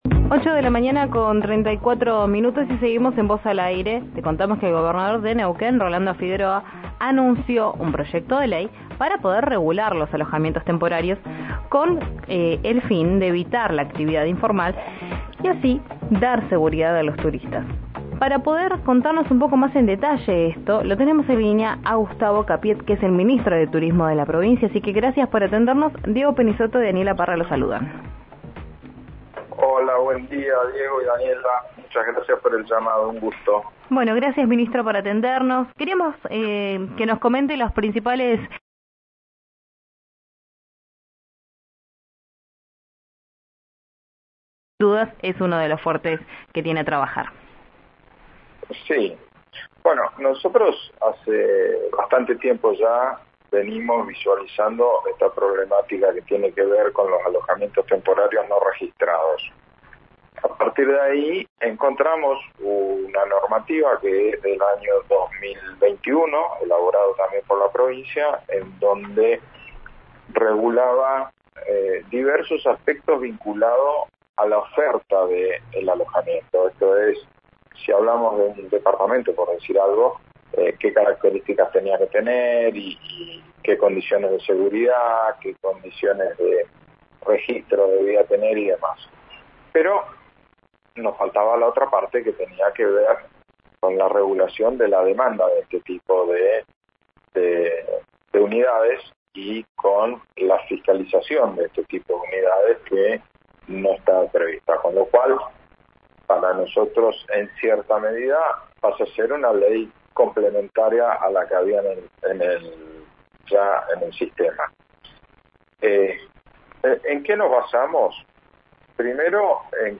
Escuchá al ministro de Turismo de Neuquén, Gustavo Fernández Capiet, en RÍO NEGRO RADIO: